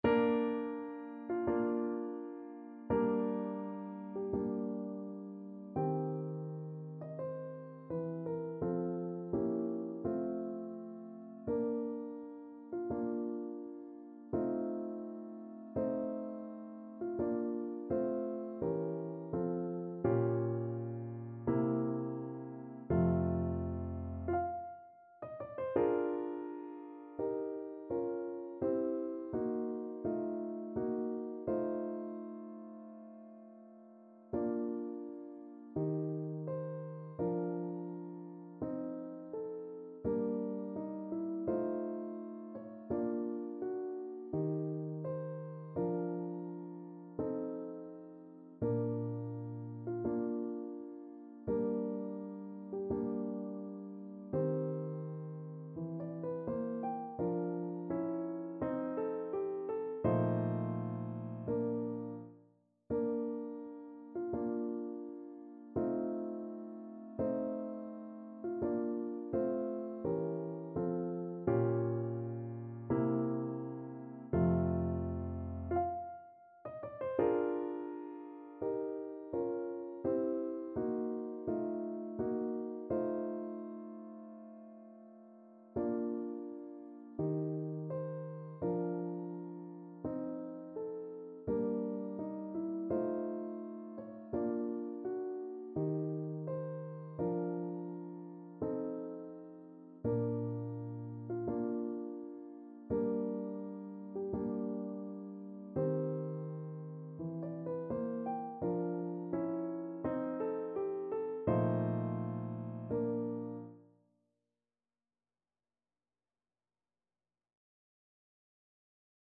2/4 (View more 2/4 Music)
~ = 42 Sehr langsam
Bb major (Sounding Pitch) C major (Trumpet in Bb) (View more Bb major Music for Trumpet )
Classical (View more Classical Trumpet Music)